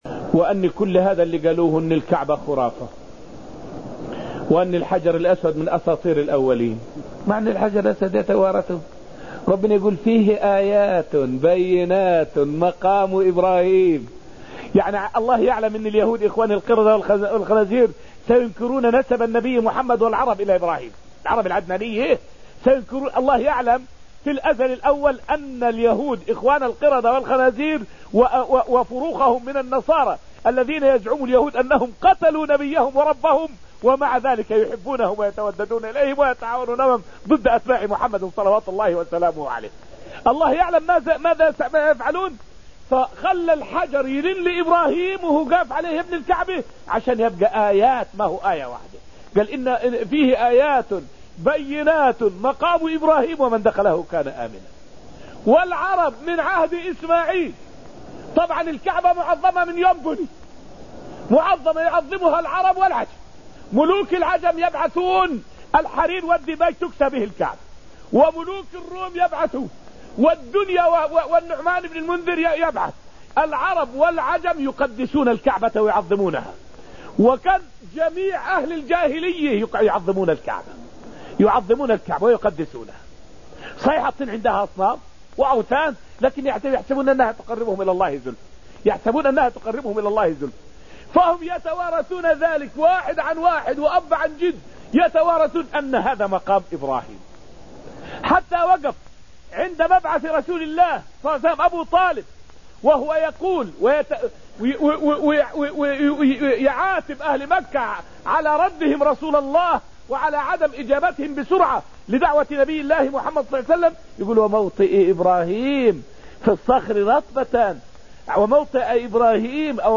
فائدة من الدرس الثالث من دروس تفسير سورة المجادلة والتي ألقيت في المسجد النبوي الشريف حول الرد على من أنكر نسب النبي والعرب إلى إبراهيم عليه السلام.